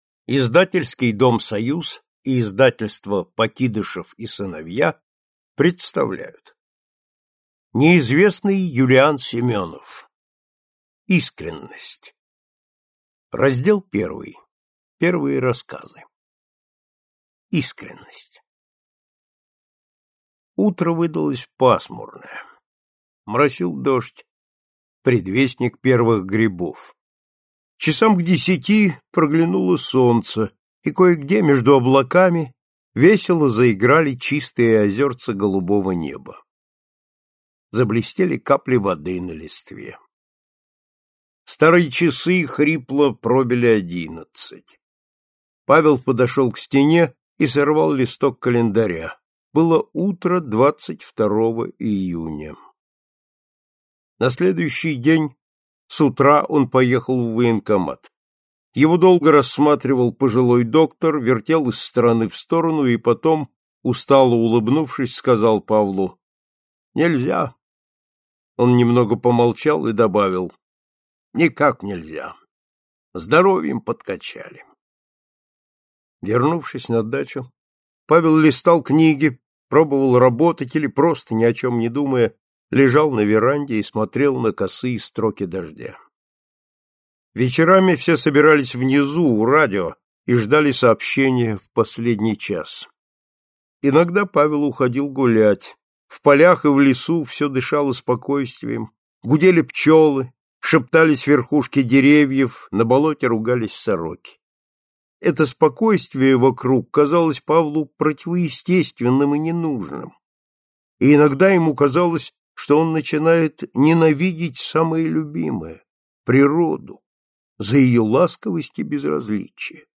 Aудиокнига Искренность